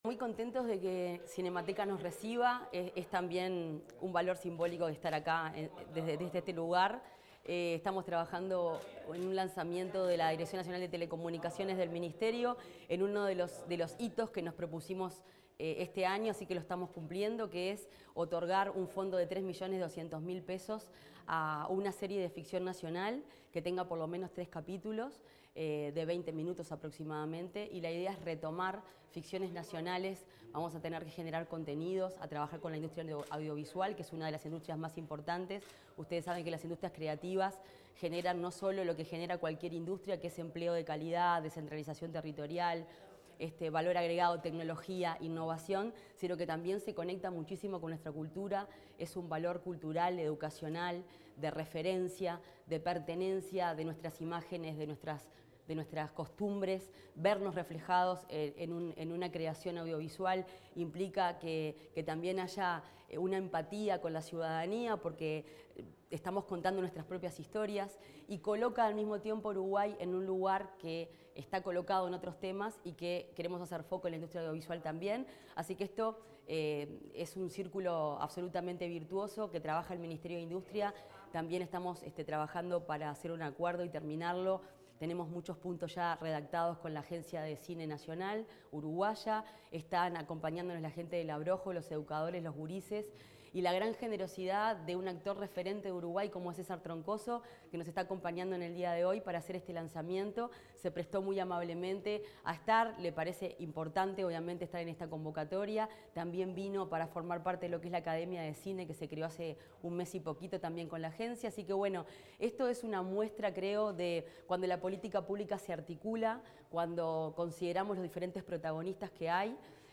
Declaraciones de la ministra Fernanda Cardona